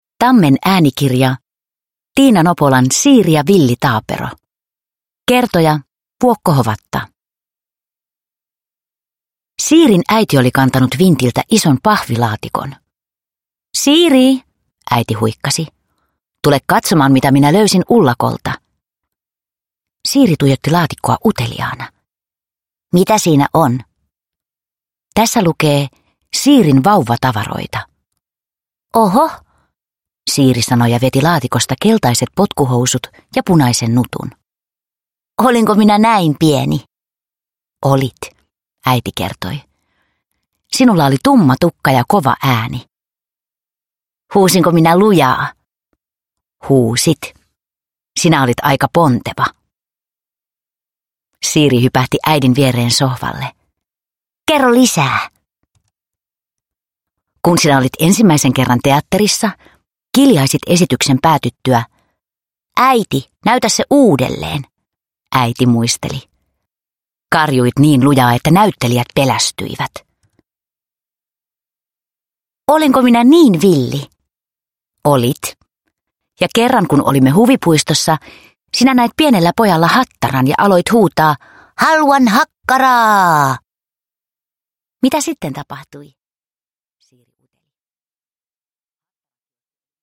Siiri ja villi taapero – Ljudbok – Laddas ner